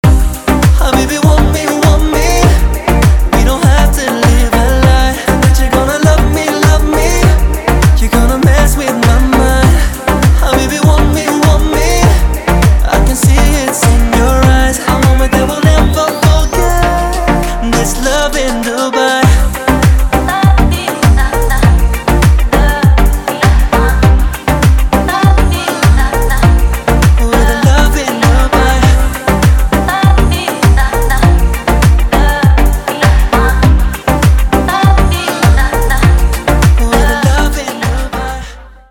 • Качество: 320, Stereo
мужской вокал
Dance Pop
восточные